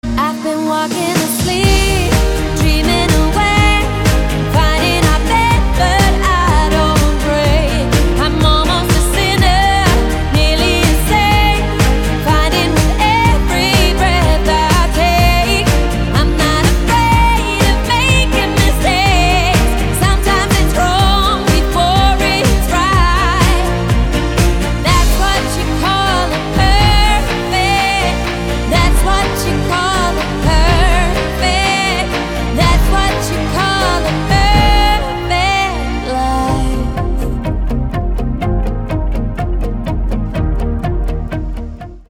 • Качество: 320, Stereo
поп
женский вокал
dance
красивый женский голос